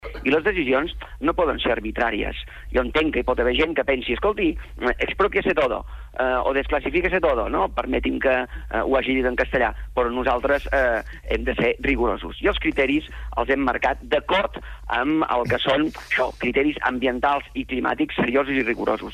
Davant d’això, Calvet s’ha preguntat en declaracions a SER Catalunya com pot ser que no estiguin satisfets amb el pas endavant que suposa el PDU.